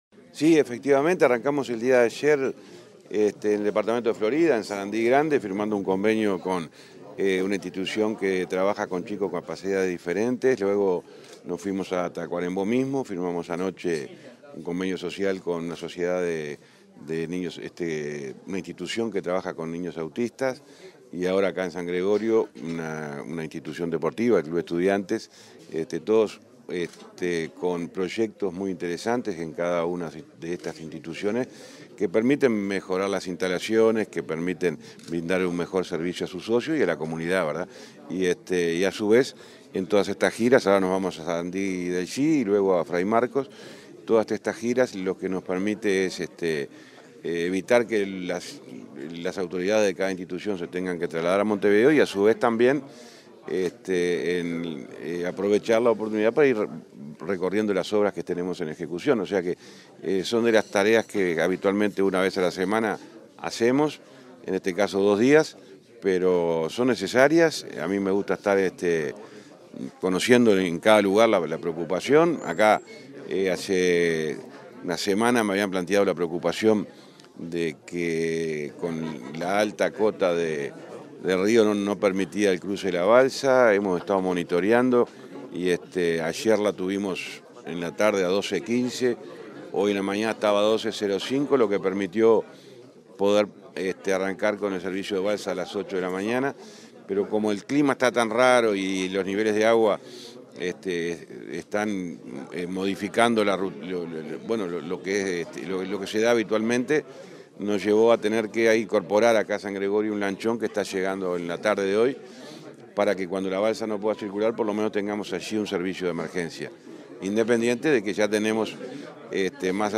Declaraciones del ministro de Transporte y Obras Públicas, José Luis Falero
Declaraciones del ministro de Transporte y Obras Públicas, José Luis Falero 24/05/2024 Compartir Facebook X Copiar enlace WhatsApp LinkedIn Tras la firma de convenios sociales y la inauguración de infraestructura con diferentes instituciones en los departamentos de Durazno y Florida, este 24 de mayo, el ministro de Transporte y Obras Pública, José Luis Falero, realizó declaraciones a la prensa.